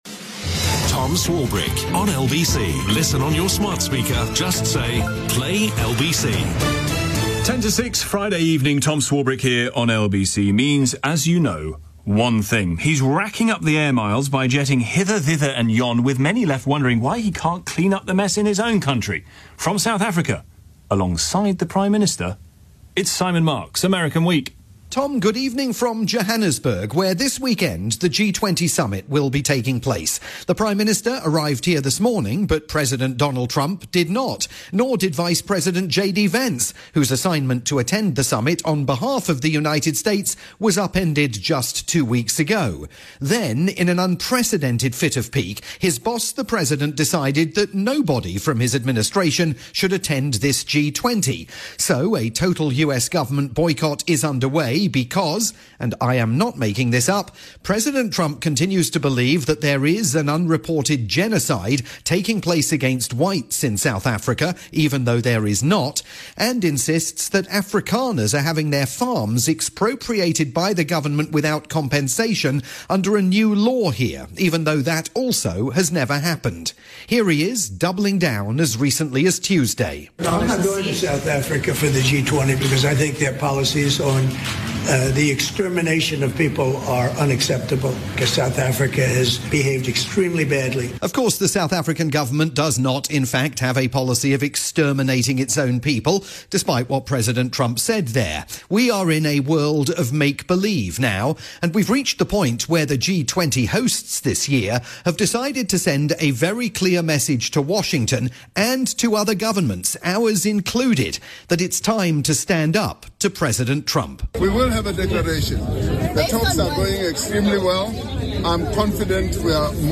weekly chronicle of events in the United States, this week reporting from Johannesburg. The rest of the world is meeting there for this weekend's G20 Summit...but not President Donald Trump or any of his government officials.